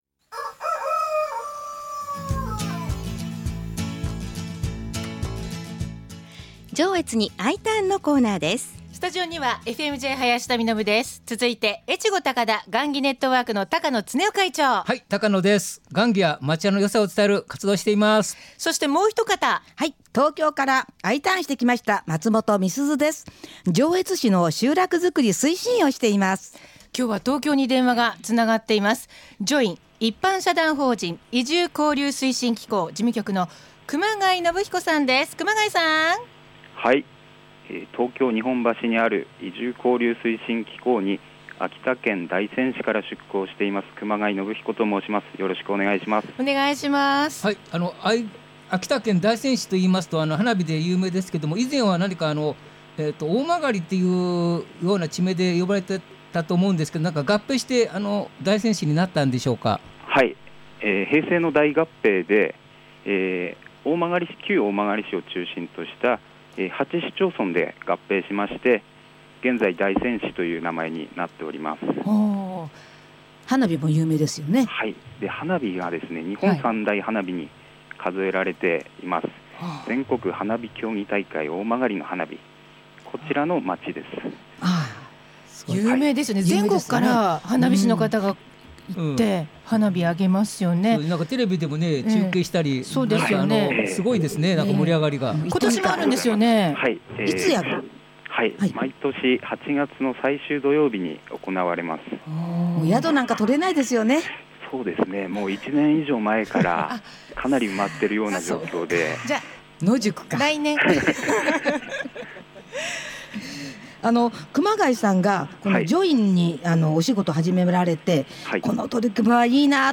基本的にはFM-Jのスタジオから移住をお誘いするコーナー。
スタジオは台本無視の大暴走！！